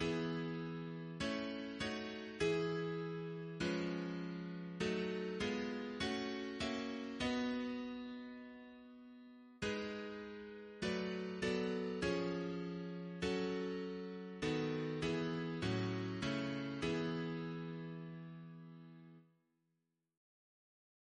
Double chant in E Composer: John Bertalot (b.1931), Organist of Blackburn Cathedral Note: for Psalm 7 Reference psalters: RSCM: 22